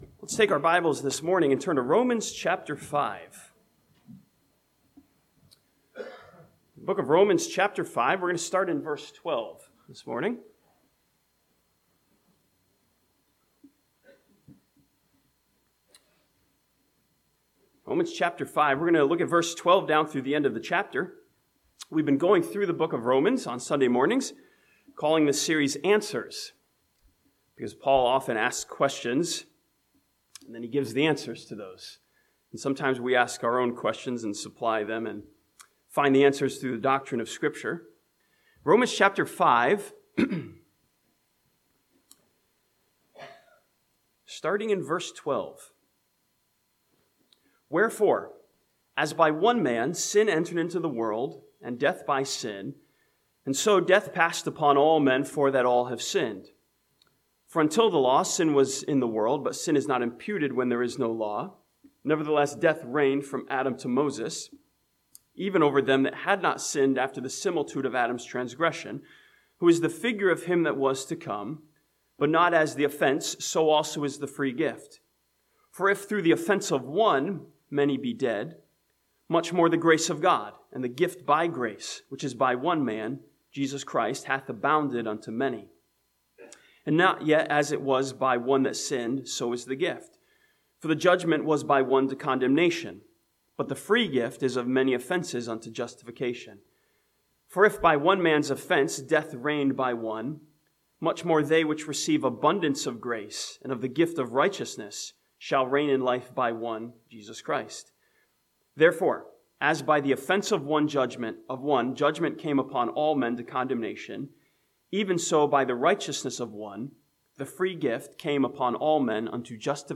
This sermon from Romans chapter 5 asks a question of accountability - Who is responsible for the way this world is?